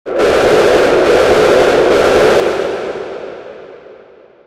tsm_summon.ogg